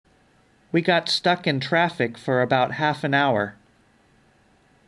子音で終わり次の単語が母音で始まる場合、自然につながって読まれます